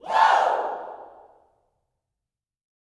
WOO  03.wav